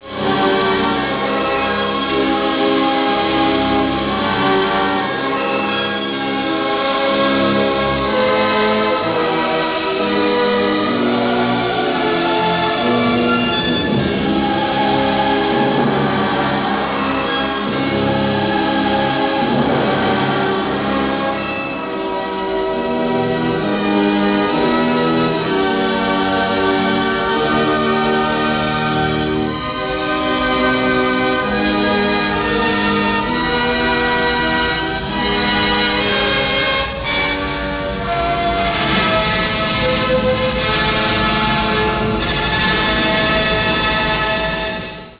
Colonna sonora
Partitura registrata in Inghilterra